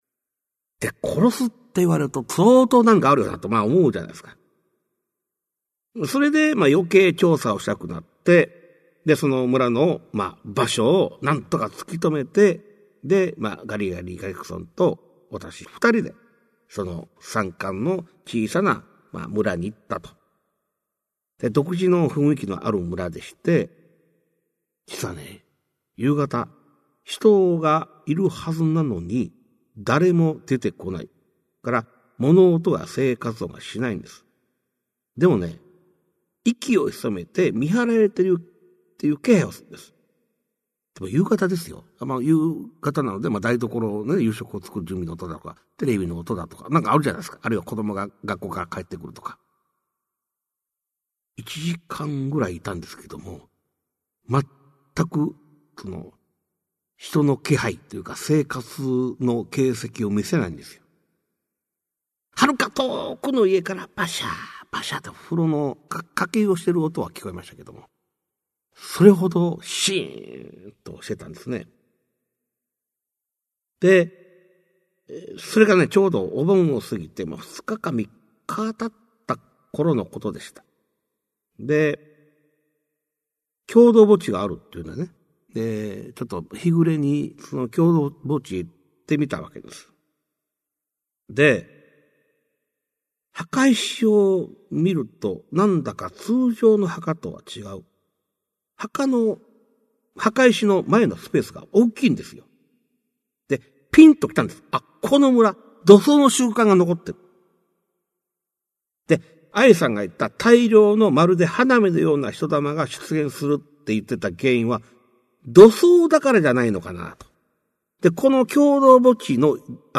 [オーディオブック] 市朗怪全集 六十